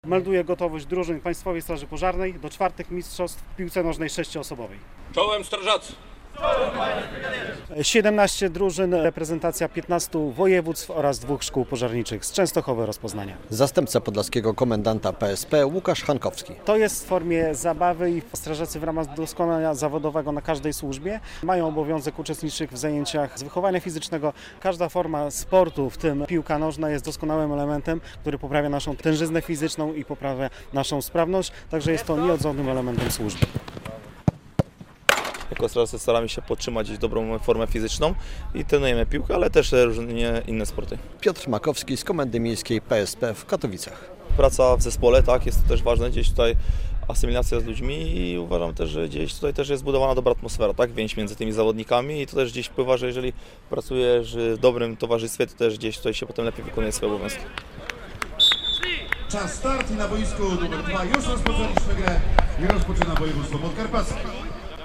IV Mistrzostwa Polski Strażaków w sześcioosobowej piłce nożnej w Białymstoku - relacja